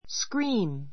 scream A2 skríːm スク リ ー ム 動詞 キャーと叫 さけ ぶ, 悲鳴をあげる scream with pain [fear] scream with pain [fear] 痛くて[怖 こわ くて]キャーと叫ぶ She screamed for help.